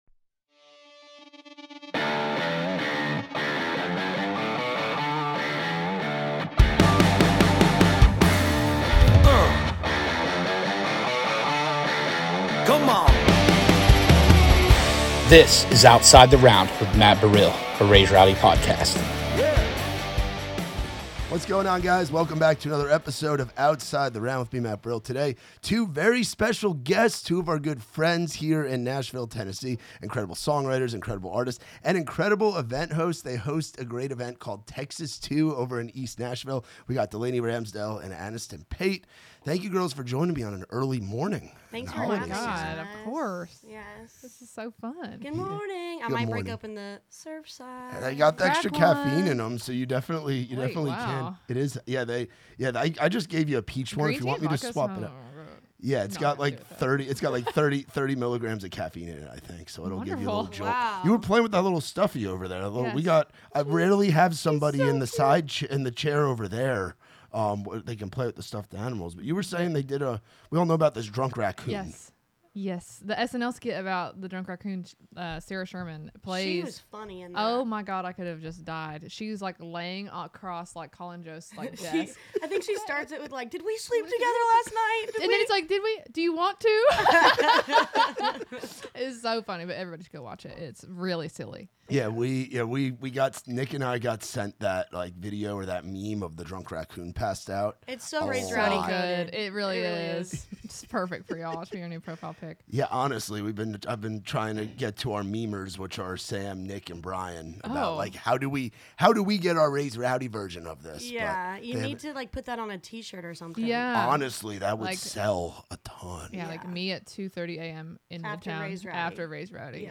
From laughs about first impressions to serious takes on what needs to change in the industry, this conversation shines a light on two rising voices who are building community, embracing independence,